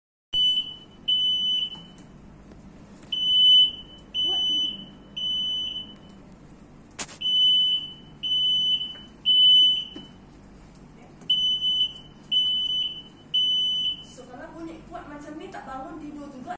Sound signal dari smoke detector sound effects free download
Sound signal dari smoke detector mengikut piagam seluruh dunia